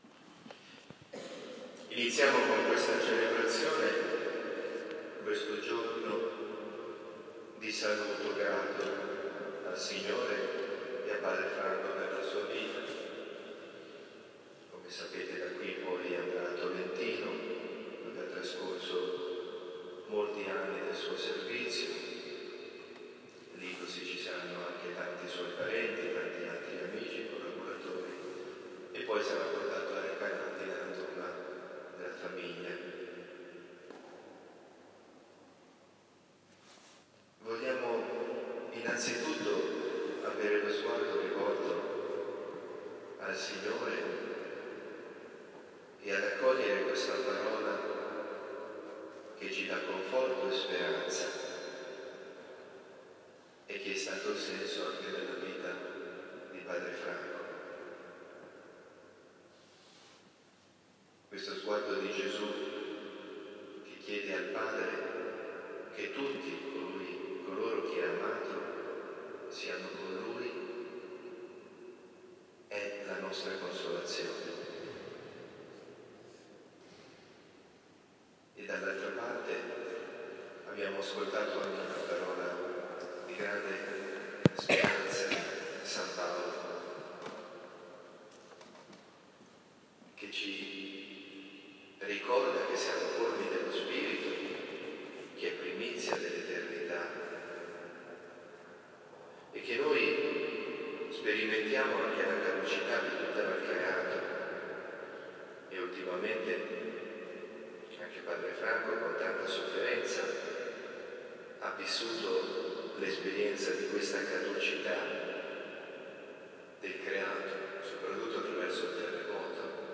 avvisi, Omelie
Basilica di San Giacomo Maggiore, ore 9
Basilica-di-San-Giacomo-Maggiore-Omelia.m4a